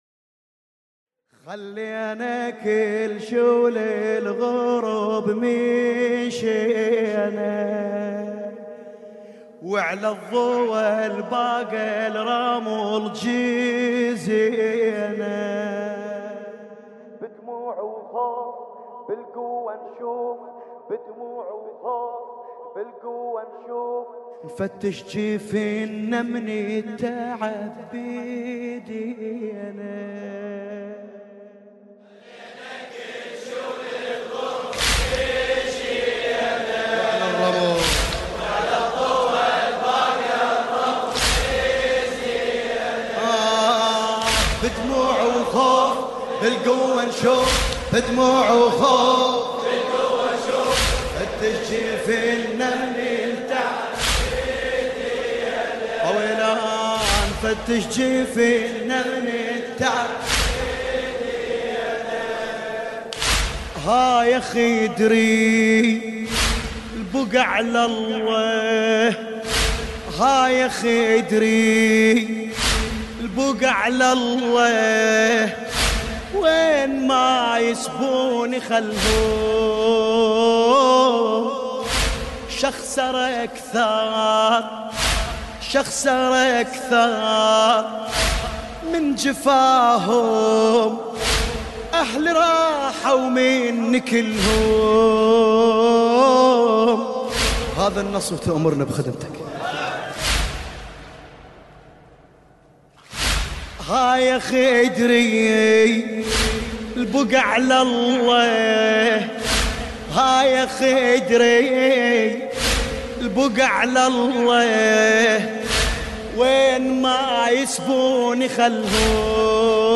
لطميات